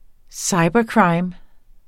Udtale [ ˈsɑjbʌˌkɹɑjm ]